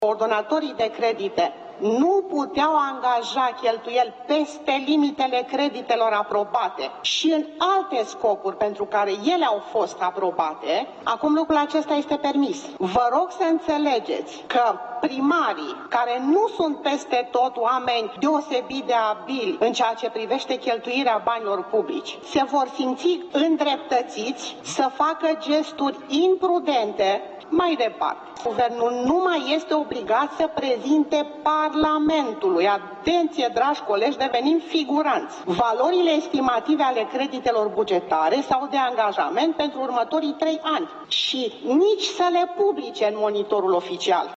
În timpul dezbaterilor, Carmen Hărău de la PNL a explicat principalele schimbări care apar odată cu aprobarea acestei ordonanțe.